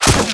bolt_hit4.wav